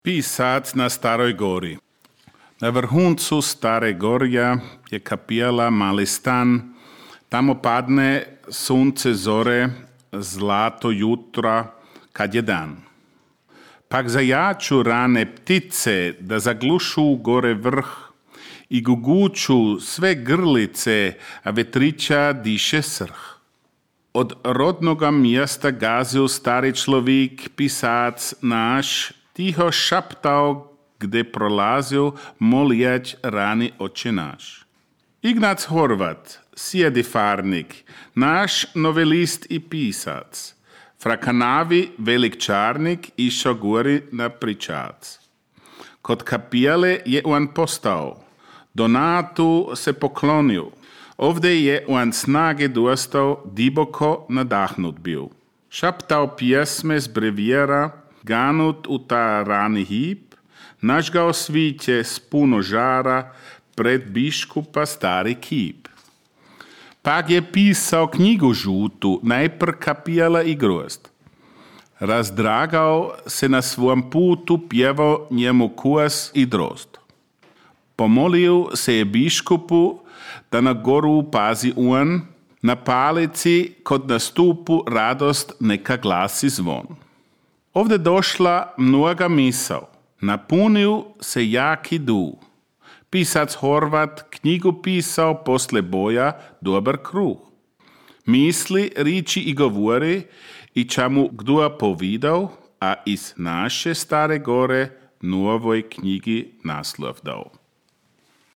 Leopold-maraton 1